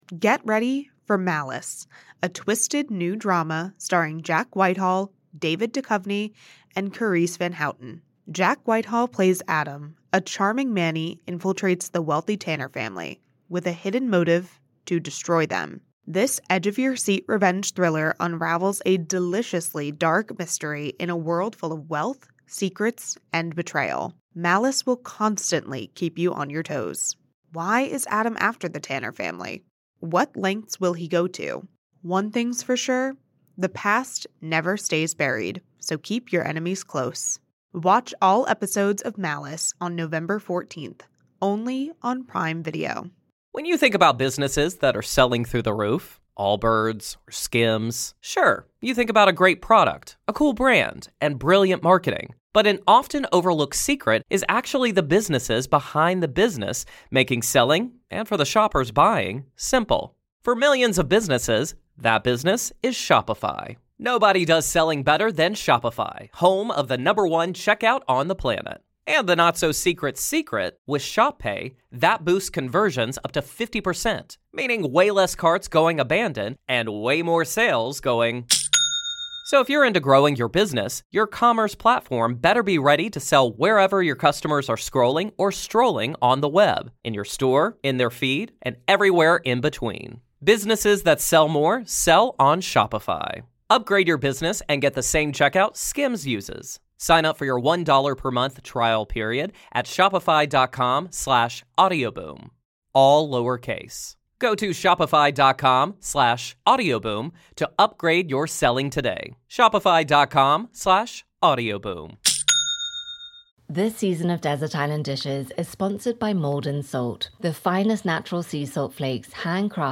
My guest today is Ruby Wax